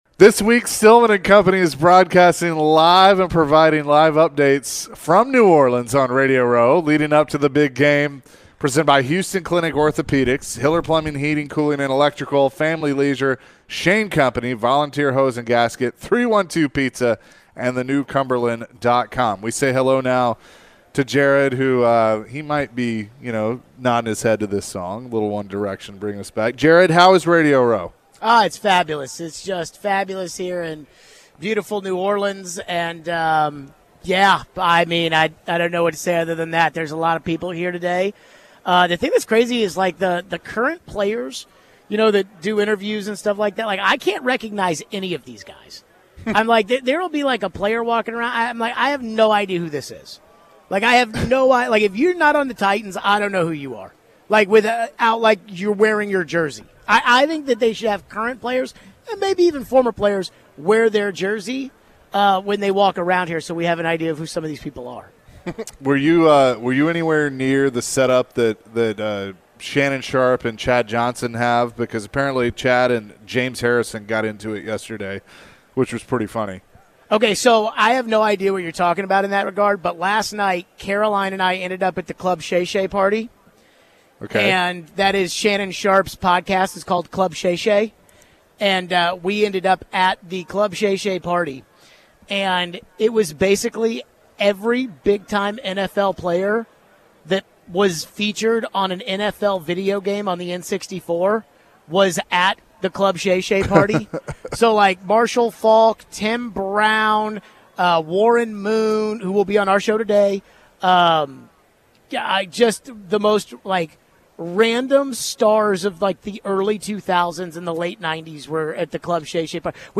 LIVE from Radio Row in New Orleans. What is the perception of the Tennessee Titans in New Orleans?